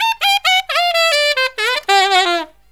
63SAXMD 08-L.wav